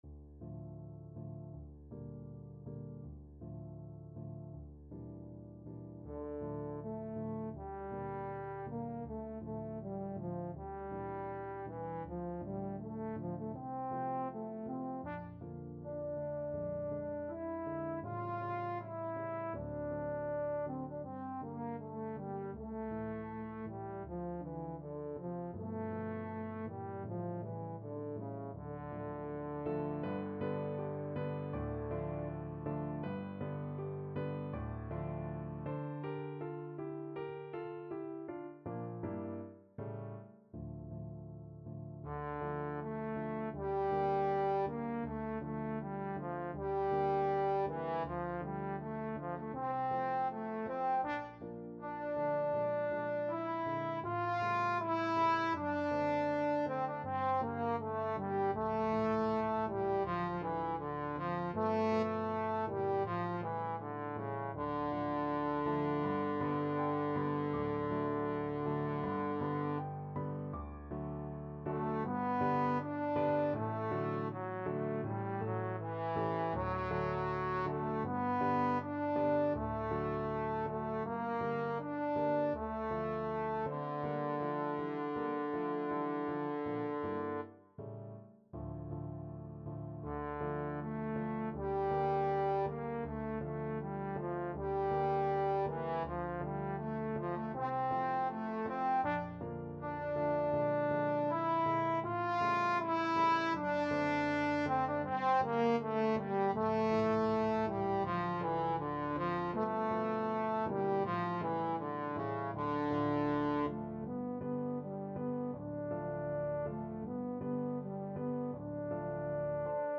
2/4 (View more 2/4 Music)
~ = 100 Andante
Classical (View more Classical Trombone Music)